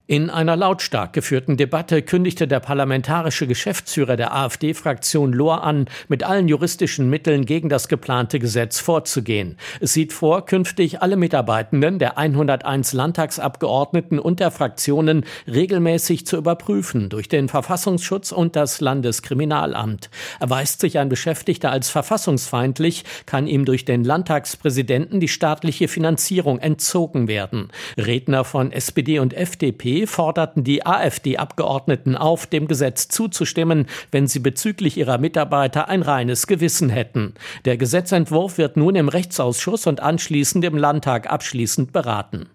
Lautstarke Landtagsdebatte über Gesetz gegen extremistische Mitarbeiter